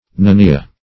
Nenia - definition of Nenia - synonyms, pronunciation, spelling from Free Dictionary Search Result for " nenia" : The Collaborative International Dictionary of English v.0.48: Nenia \Ne"ni*a\, n. [L. nenia, naenia.]